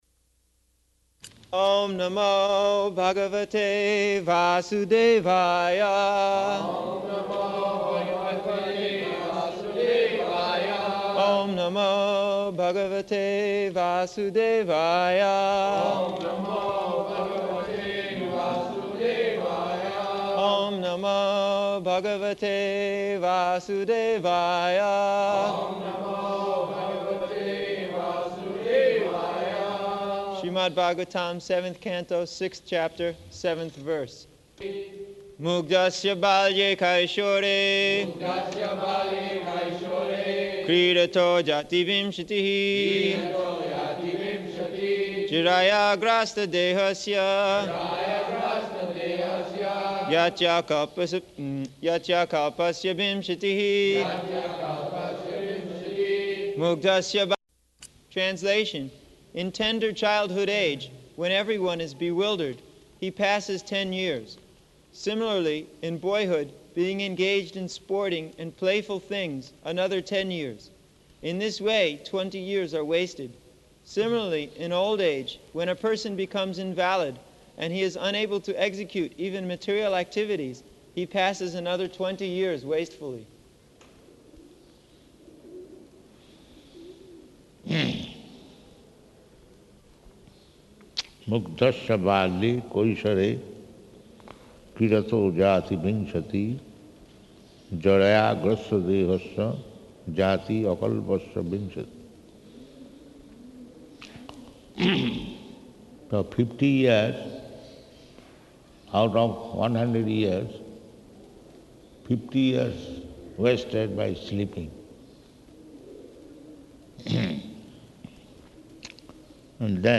December 9th 1975 Location: Vṛndāvana Audio file
[devotees repeat] Śrīmad-Bhāgavatam, Seventh Canto, Sixth Chapter, seventh verse.